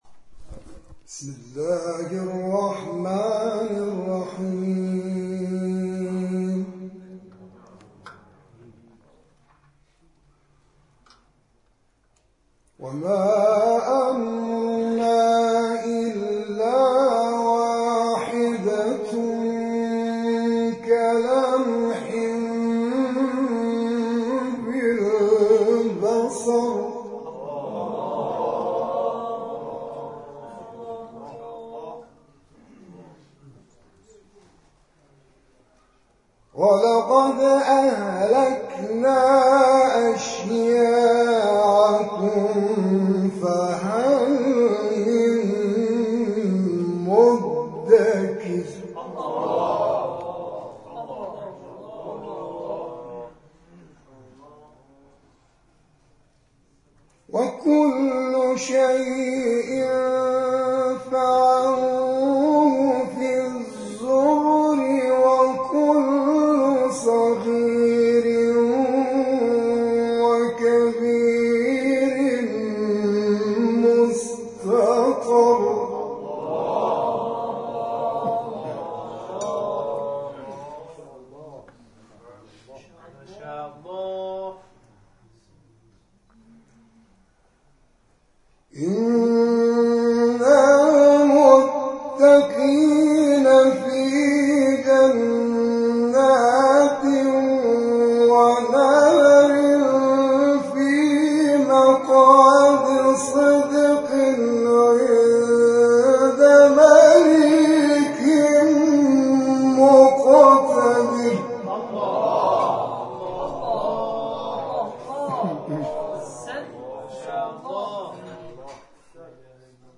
آیاتی از کلام الله مجید را تلاوت کرد و مورد تشویق حاضران در جلسه قرار گرفت
تلاوت